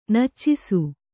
♪ naccisu